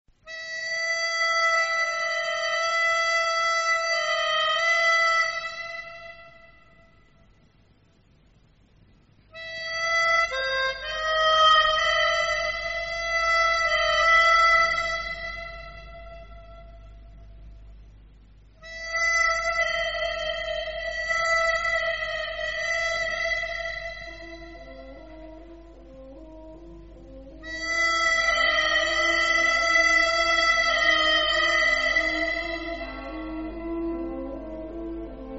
Kategorien: Filmmusik